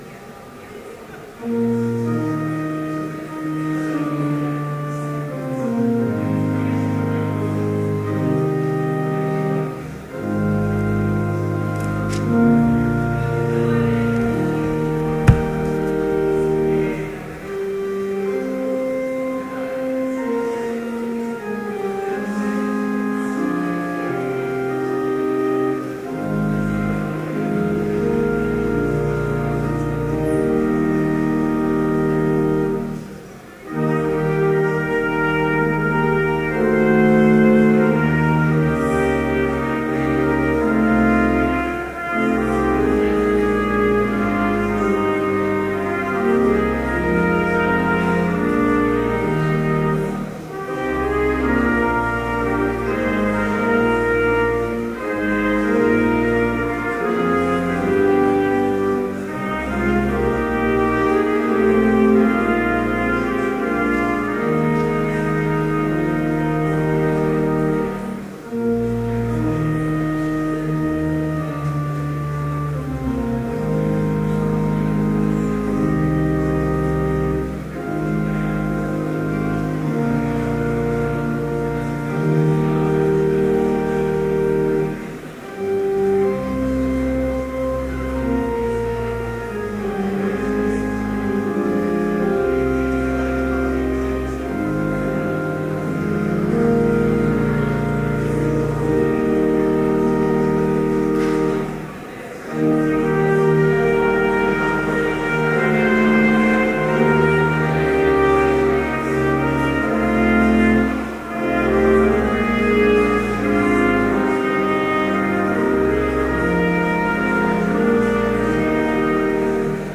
Complete service audio for Chapel - November 1, 2013